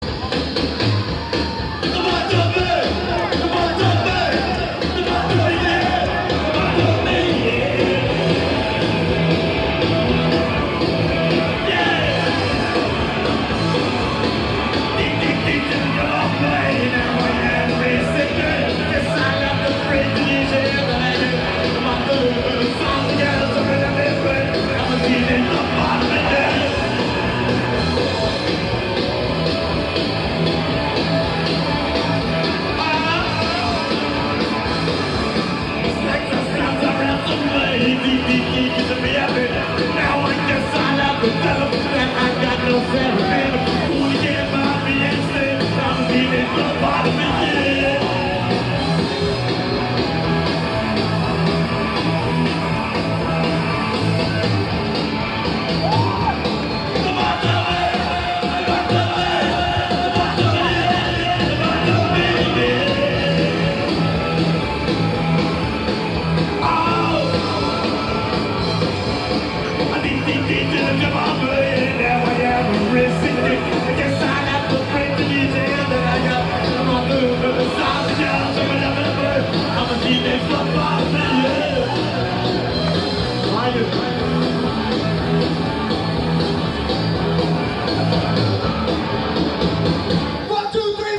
Chestnut Cabaret Philadelphia 12-13-87